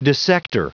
Prononciation du mot dissector en anglais (fichier audio)
Prononciation du mot : dissector